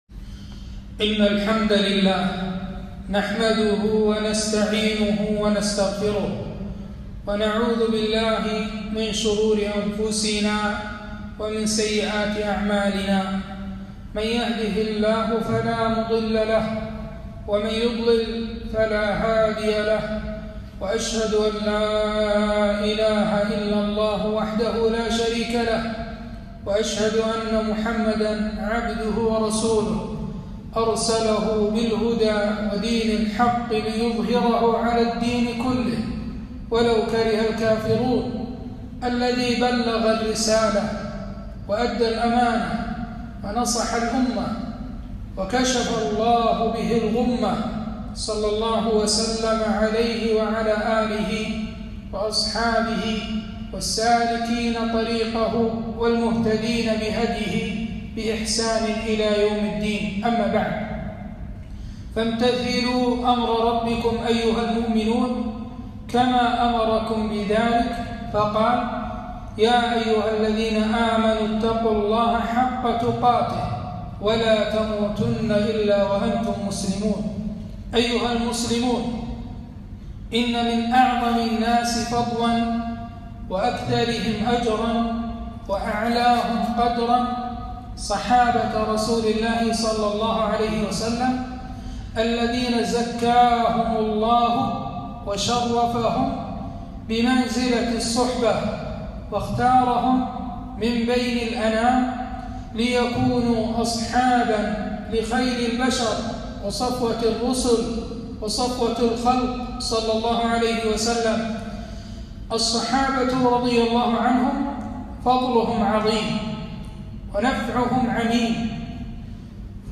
خطبة - عثمان بن عفان صحبه صادقة وخلافة راشدة